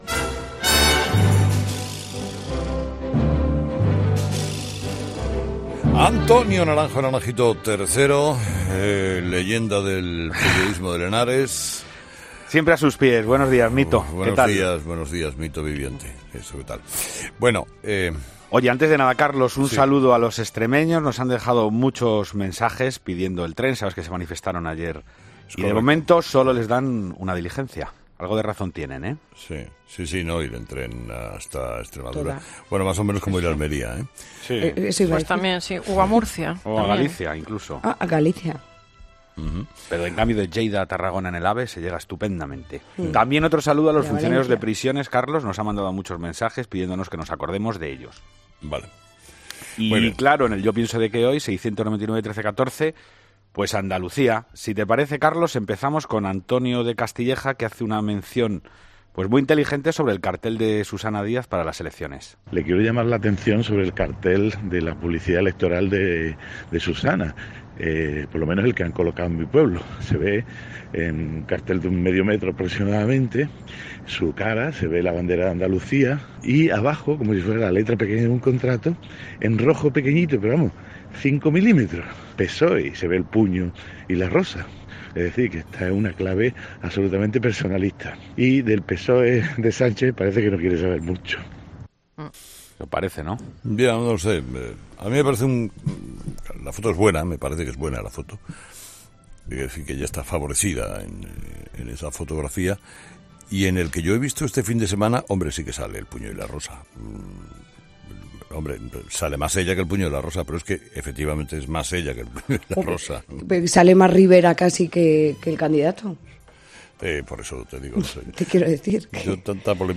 Los seguidores de Carlos Herrera han vuelto a levantar la voz en el espacio que tienen en el programa. Hoy, críticas a la situación política de Andalucía.